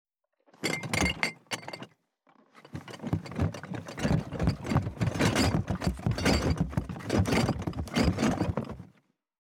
177,荷物運び,段ボール箱の中身,部署移動,キー,ザザッ,ドタドタ,バリバリ,カチャン,ギシギシ,ゴン,ドカン,ズルズル,タン,パタン,ドシン,
効果音荷物運び